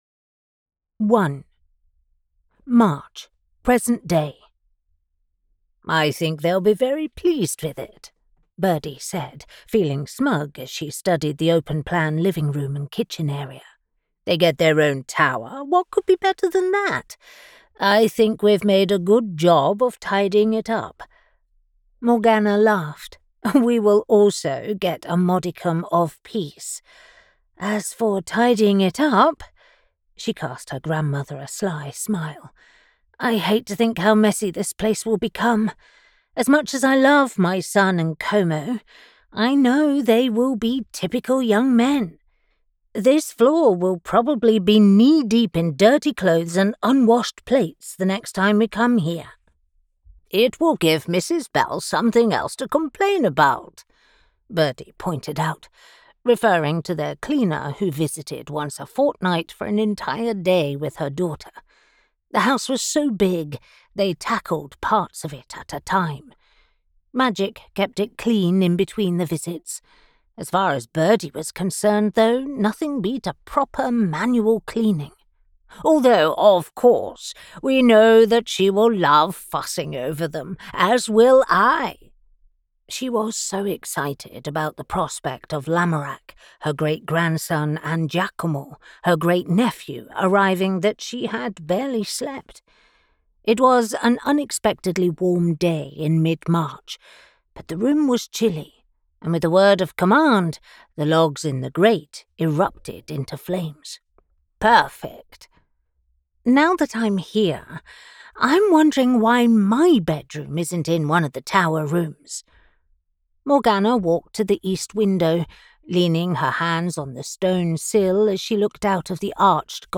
Triple Moon: Honey Gold and Wild Audiobook